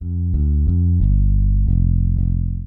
描述：用果味循环制作的冷酷的真正的低音线
Tag: 90 bpm Chill Out Loops Bass Loops 459.52 KB wav Key : F